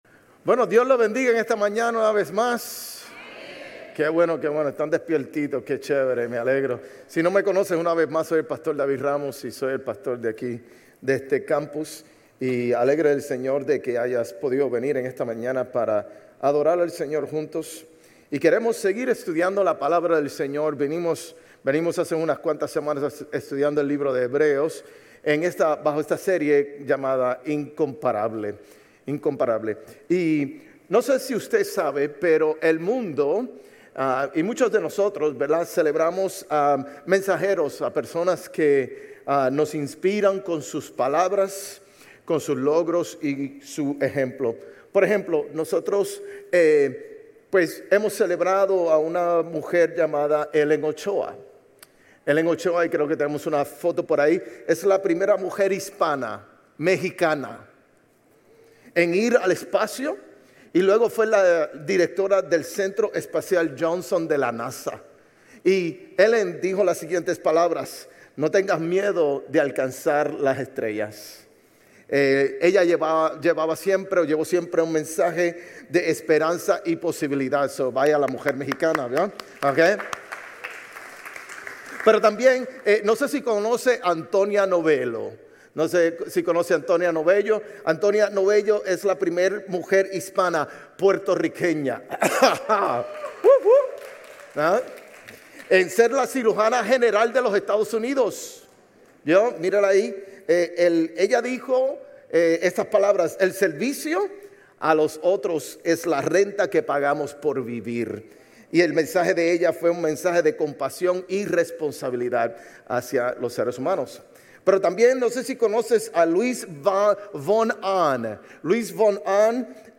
Sermones Grace Español 10_5 Grace Espanol Campus Oct 06 2025 | 00:39:24 1x 00:00 / 00:39:24 Subscribe Share RSS Feed Share Link Embed